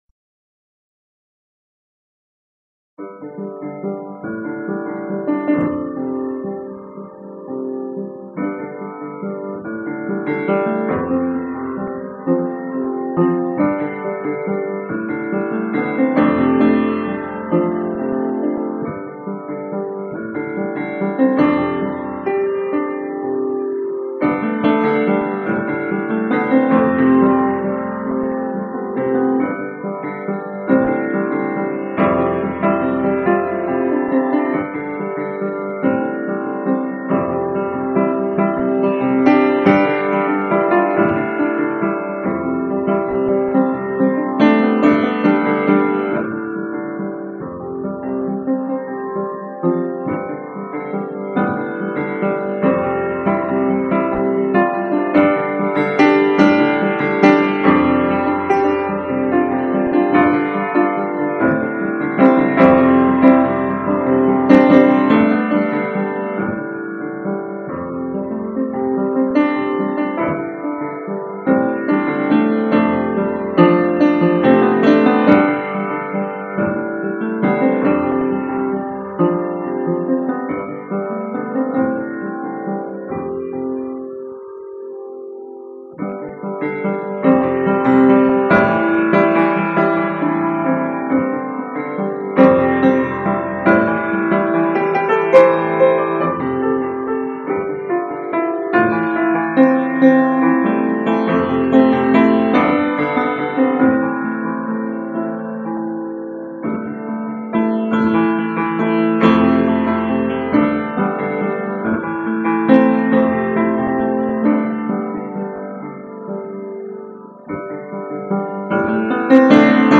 Music. I compose my own music for piano, although I use the term loosely.